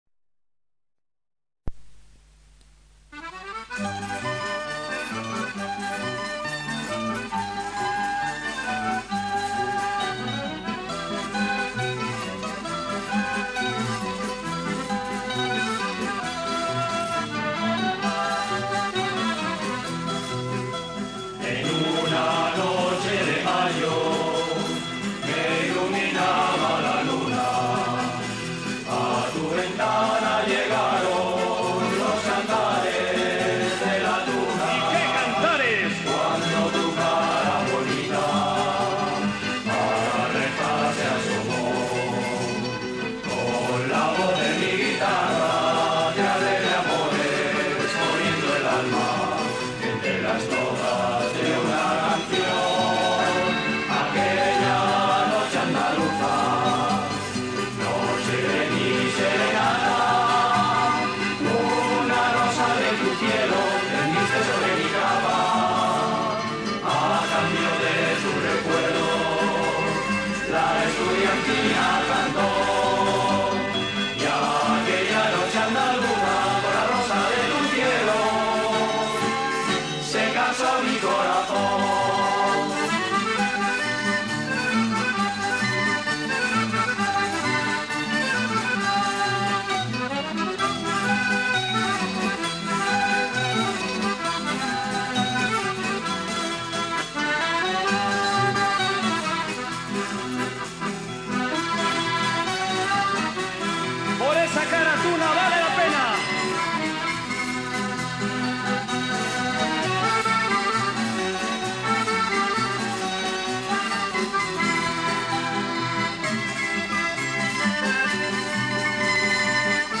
En verano de 1981 grabamos algunas canciones.